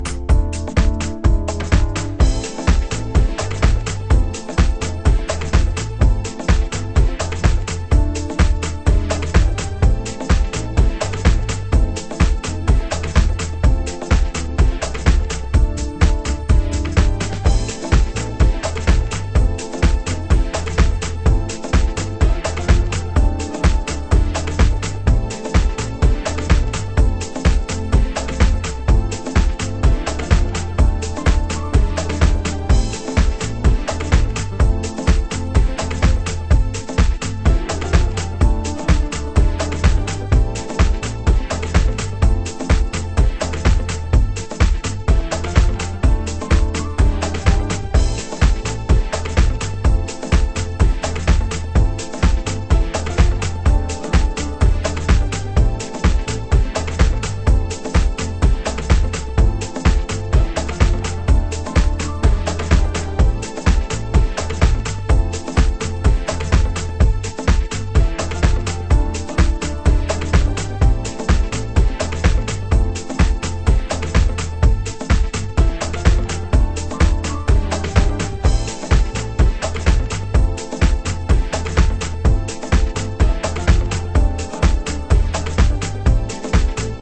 ★DEEP HOUSE 歌 WHITE